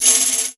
Shaken Tamb 05.wav